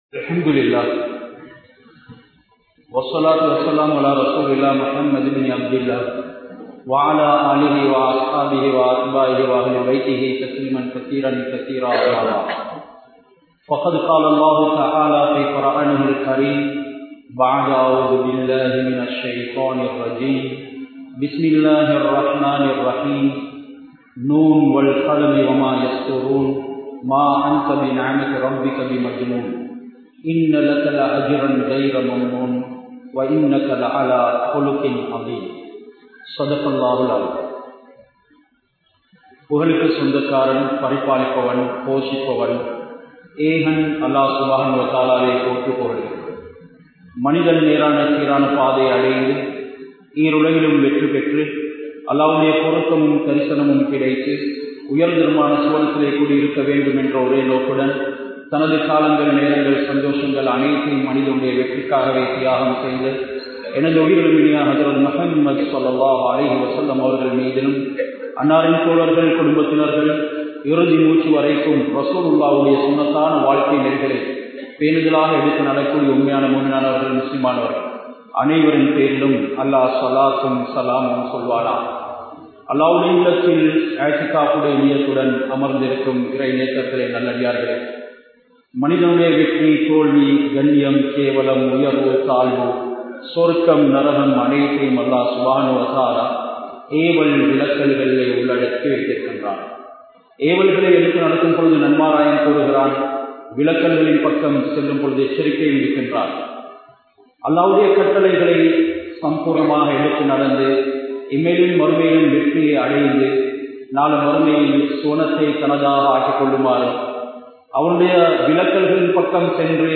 Ellai Meeriya Aasaihal (எல்லை மீறிய ஆசைகள்) | Audio Bayans | All Ceylon Muslim Youth Community | Addalaichenai
Colombo 10, Maligawatttha, Grand Jumua Masjidh